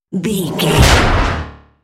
Dramatic creature hit trailer
Sound Effects
Atonal
heavy
intense
dark
aggressive
hits